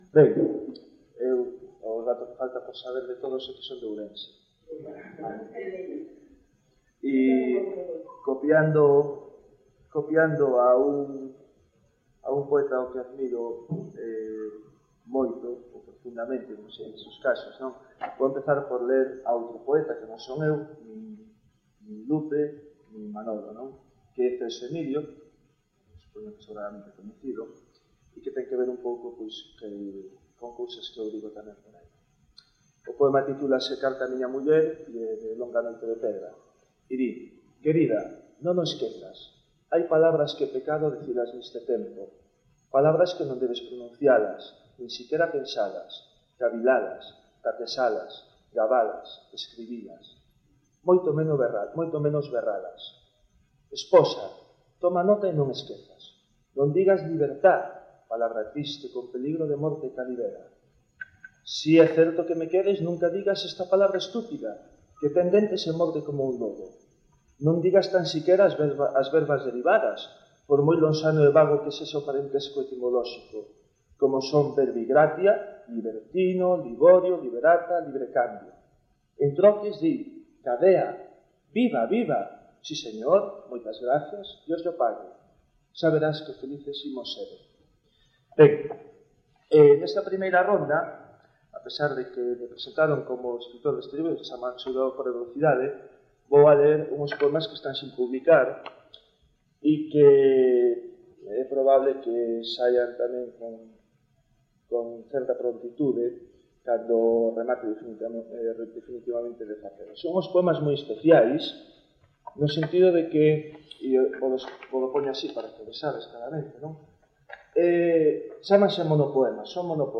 PoesiaGalega: Recital no Tarasca